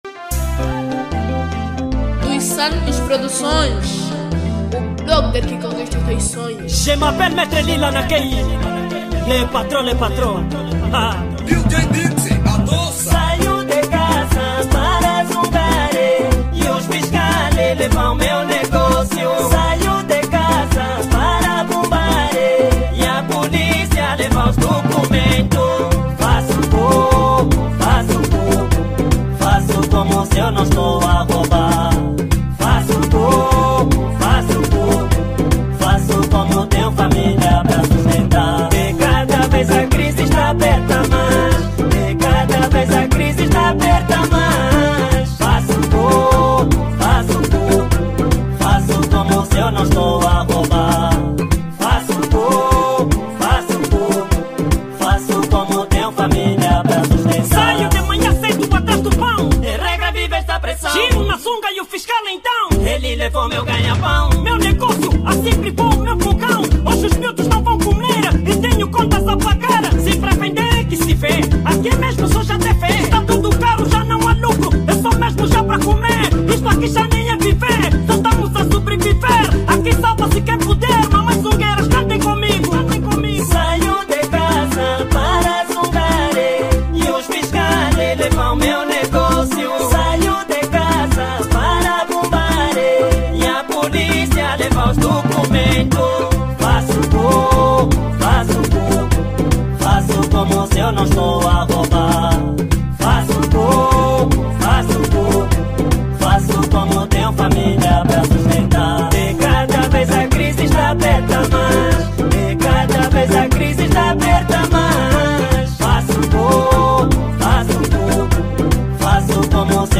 Categoria: Kuduro